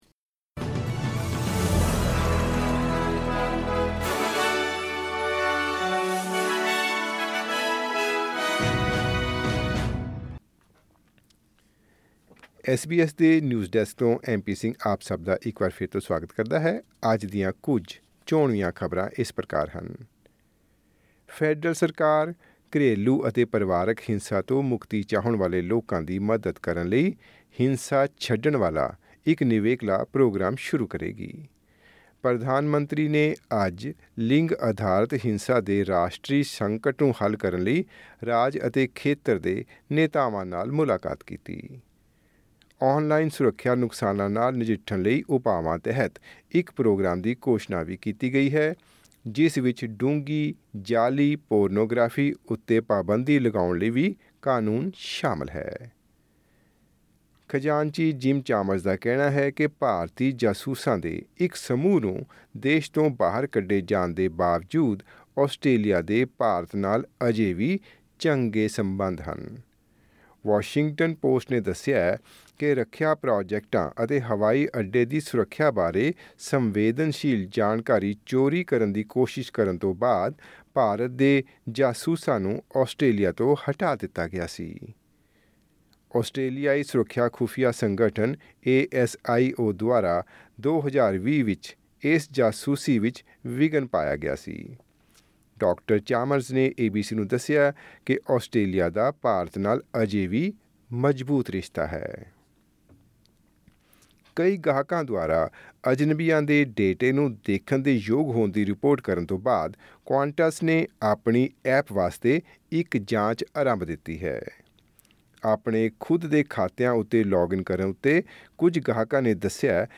ਐਸ ਬੀ ਐਸ ਪੰਜਾਬੀ ਤੋਂ ਆਸਟ੍ਰੇਲੀਆ ਦੀਆਂ ਮੁੱਖ ਖ਼ਬਰਾਂ: 1 ਮਈ, 2024